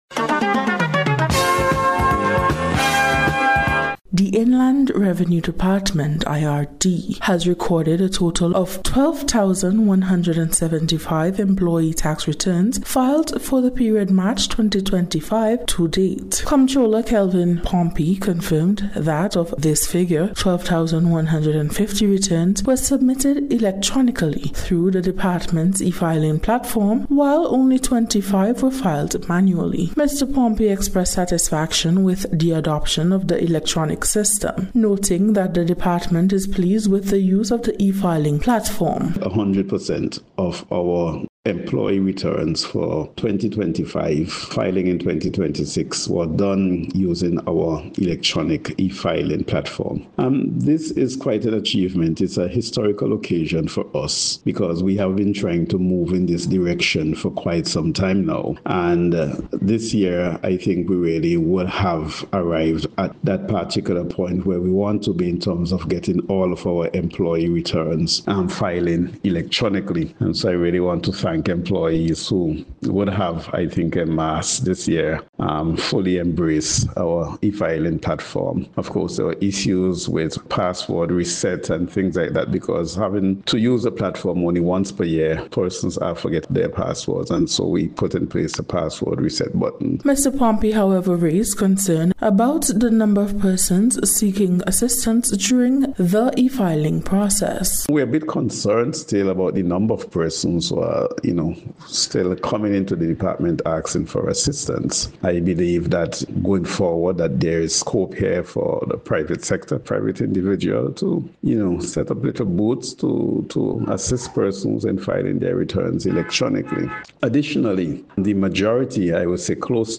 IRD-E-FILING-SPECIAL-REPORT.mp3